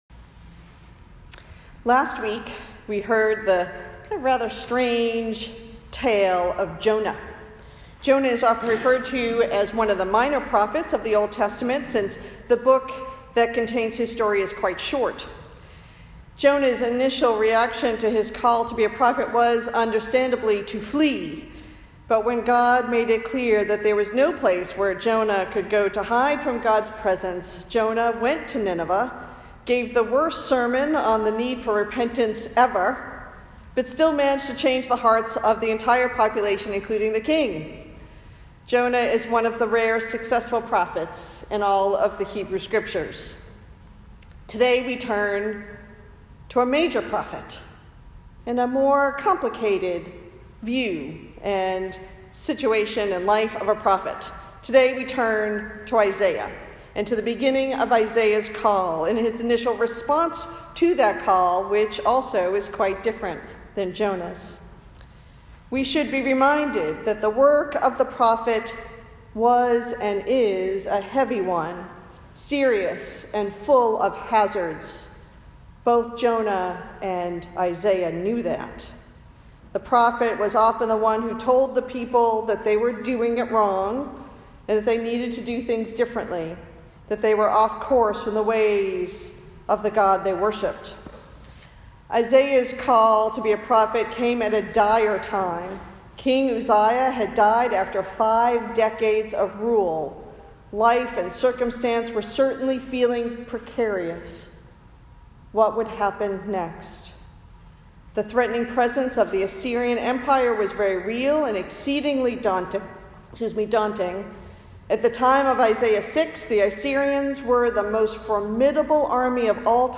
Related Posted in Sermons (not recent)